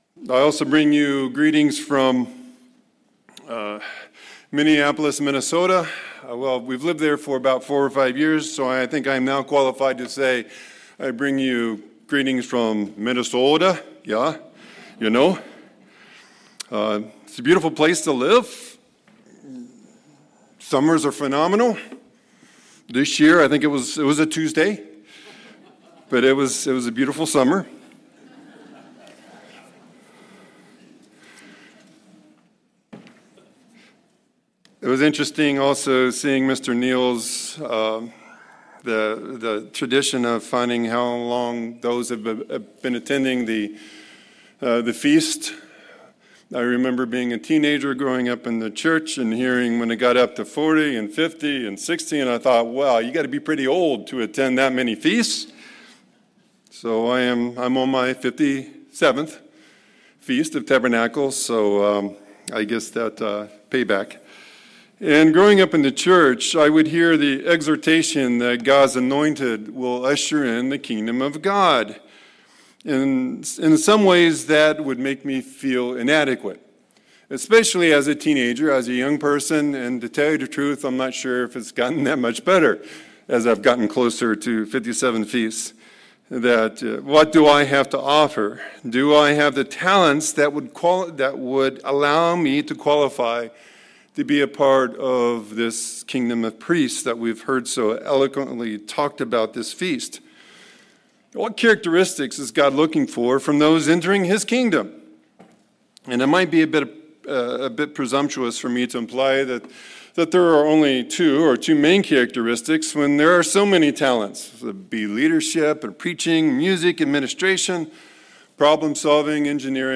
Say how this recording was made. Given in Morehead City, North Carolina